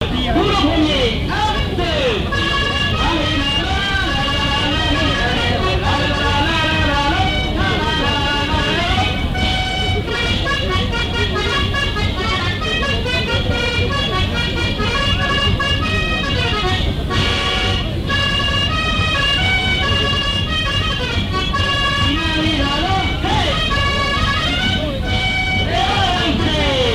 danse : quadrille : poule
lors d'une kermesse
Pièce musicale inédite